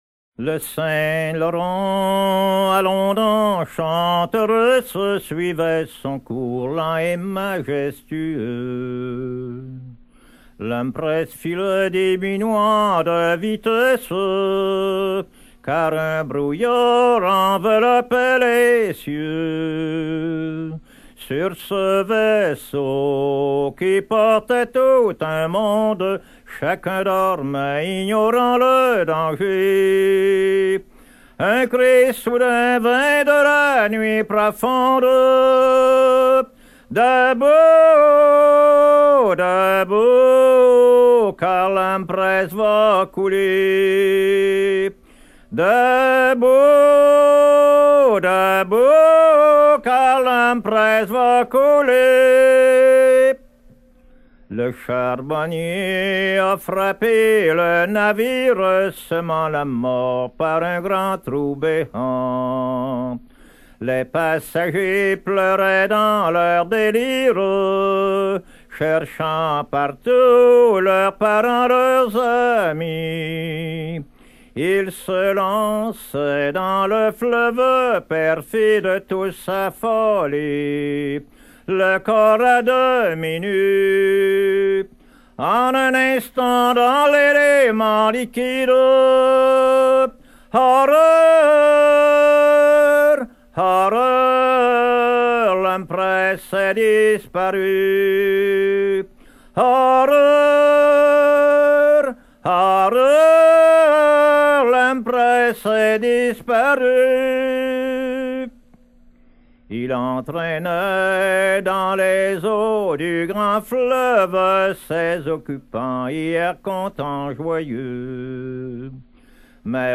Catégorie Pièce musicale éditée